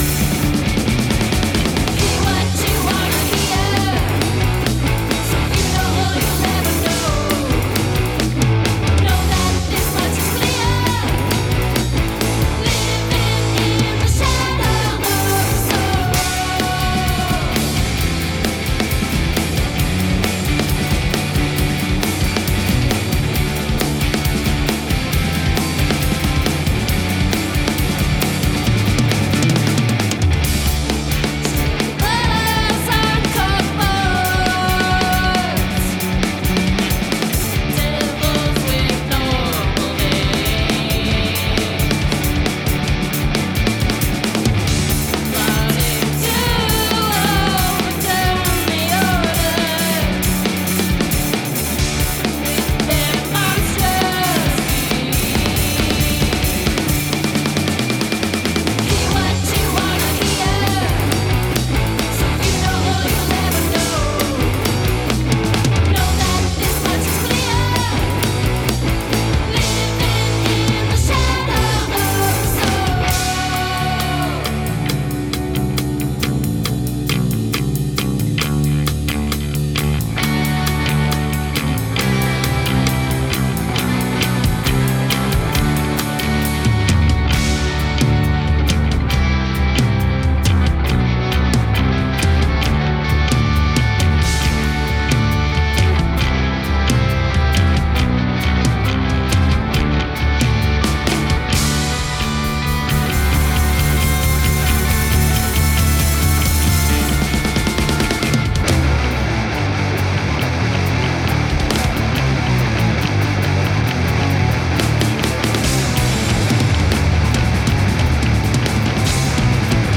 gothic post-punk rock